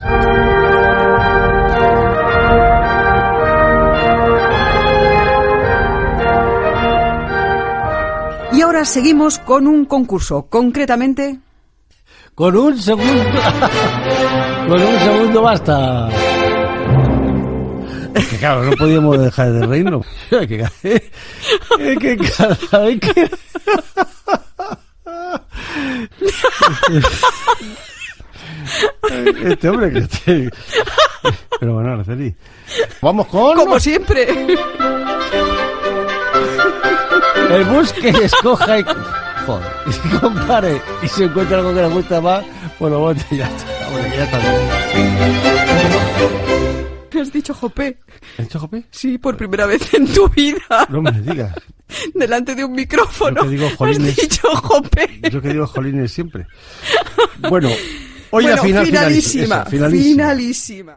Concurs "Con un segundo basta"
Musical